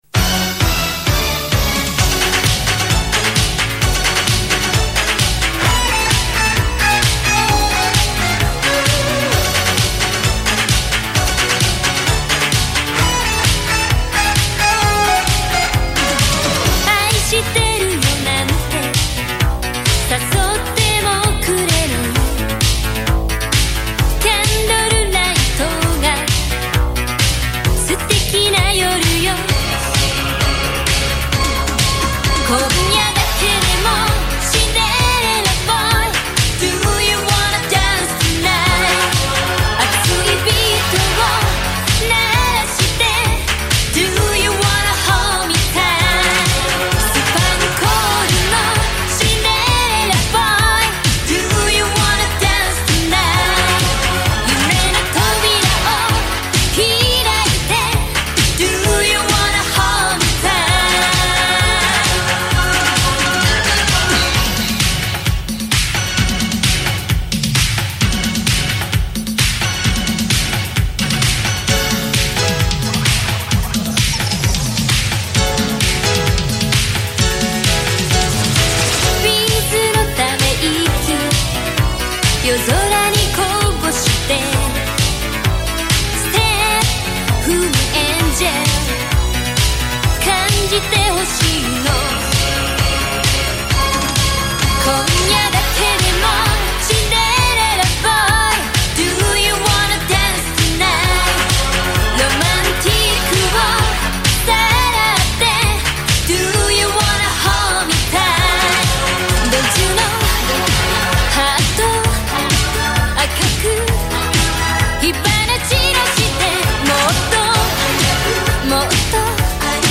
BPM131
Audio QualityPerfect (Low Quality)